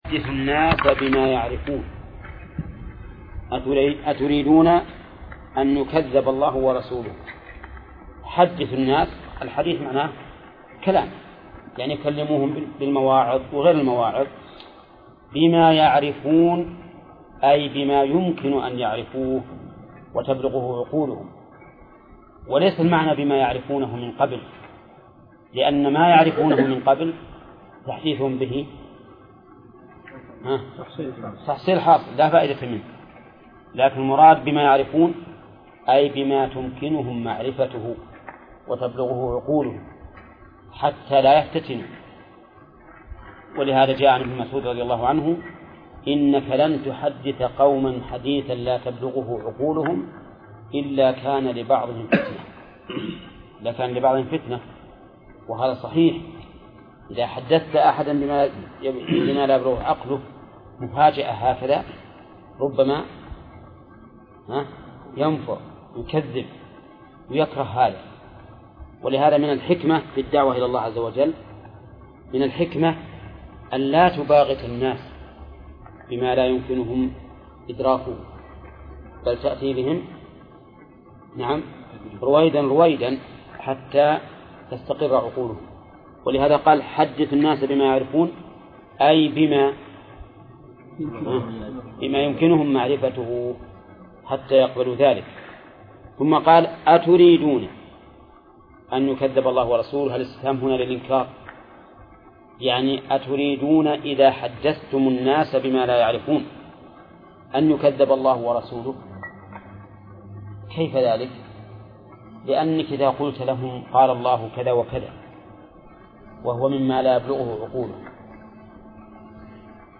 درس (38) / المجلد الثاني : من صفحة: (187)، قوله: (البحث في صفات الله:..).، إلى صفحة: (208)، قوله: (باب قوله تعالى: {فلا تجعلوا لله أندادا ..}).